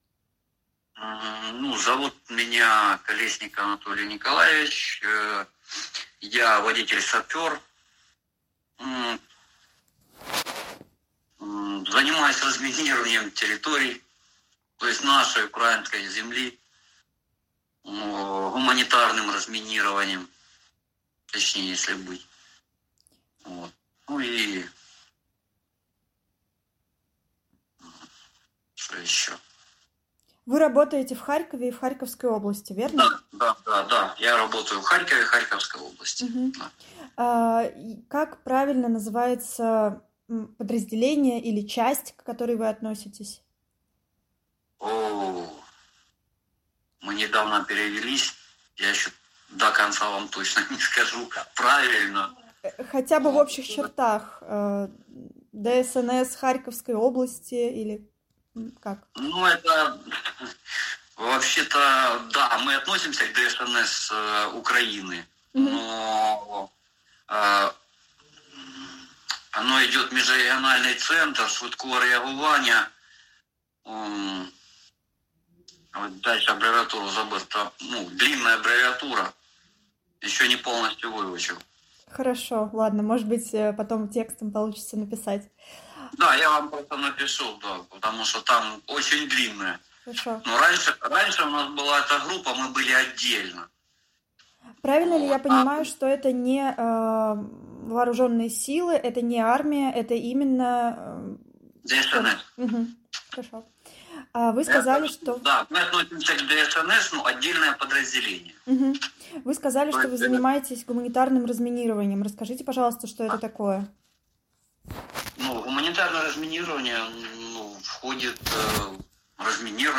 Понял, что нахожусь на минном поле — личные свидетельства войны в Украине, архив «Службы поддержки»